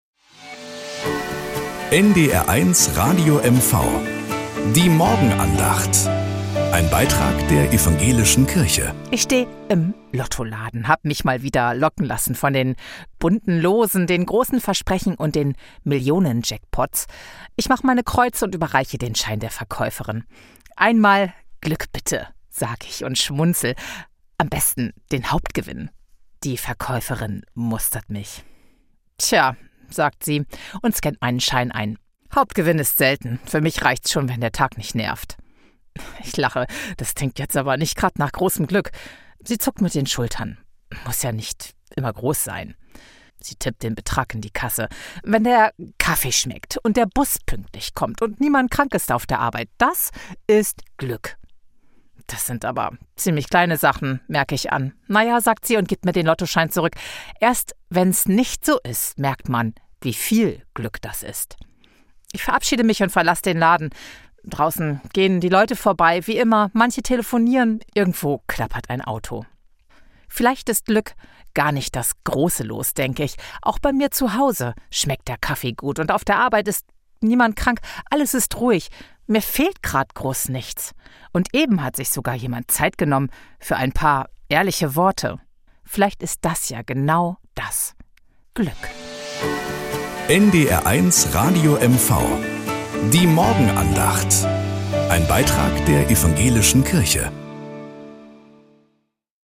Morgenandacht bei NDR 1 Radio MV